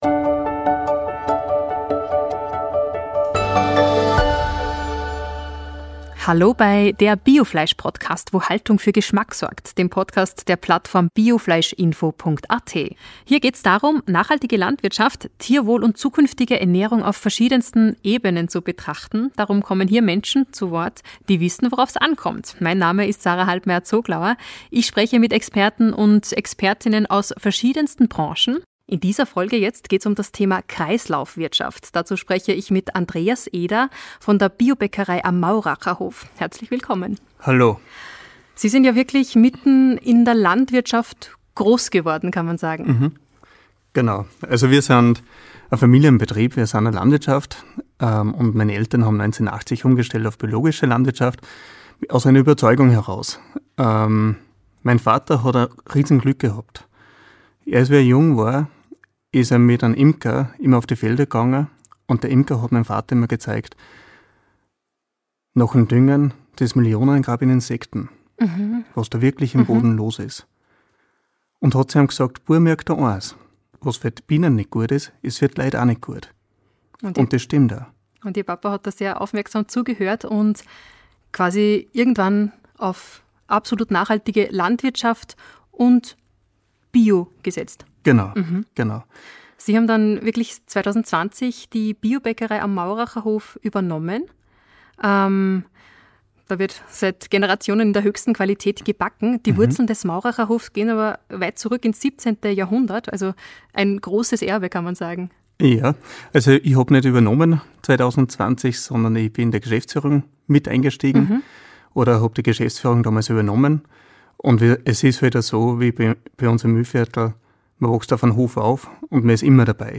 Echte Stimmen aus der Bio-Branche: Wir sprechen mit Menschen, die nachhaltige Landwirtschaft, Tierwohl und bewussten Konsum leben. Locker, ehrlich, informativ – für alle, die wissen wollen, wo gutes Essen herkommt.